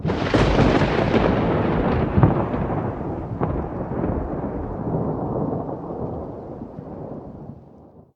thunder_medium.ogg